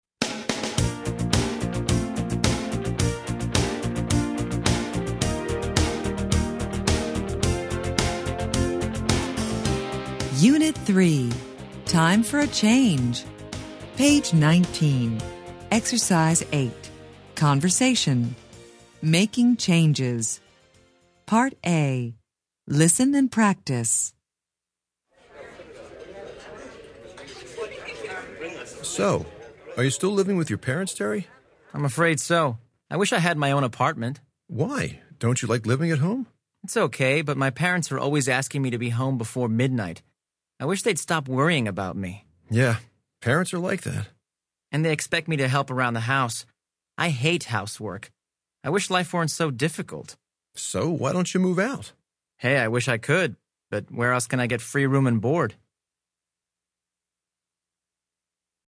American English
Interchange Third Edition Level 2 Unit 3 Ex 8 Conversation Track 5 Students Book Student Arcade Self Study Audio